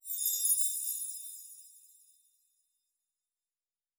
Fantasy Interface Sounds
Magic Chimes 01.wav